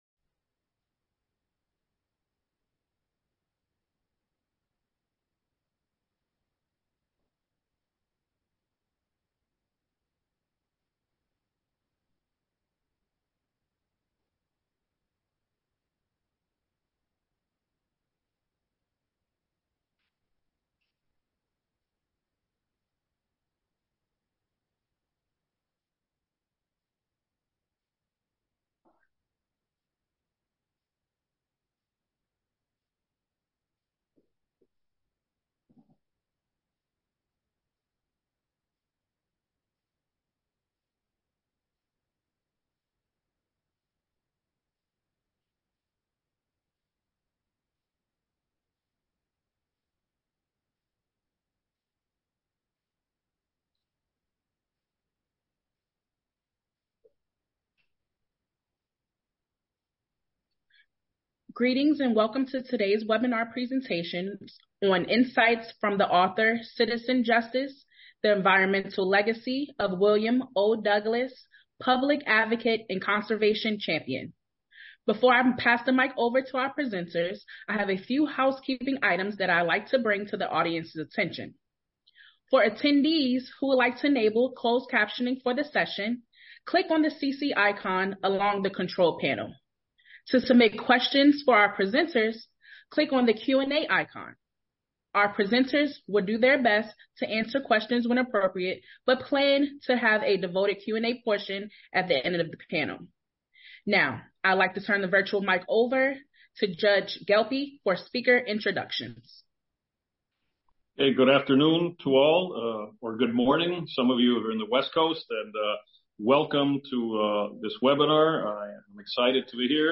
Join FBA National Past President Hon. Gustavo A. Gelpi, Judge, First Circuit Court of Appeals, as he interviews Senior Judge M. Margaret McKeown of the Ninth Circuit Court of Appeals, author of Citizen Justice: The Environmental Legacy of William O. Douglas . Judge McKeown will share insights into how Justice Douglas defended his role as a citizen advocate for conservation issues while he also used his long tenure on the U.S. Supreme Court (1939-1975) to rule on landmark environmental law decisions. The conversation will also explore ethical issues faced by Justice Douglas as he juggled is roles as advocate and justice.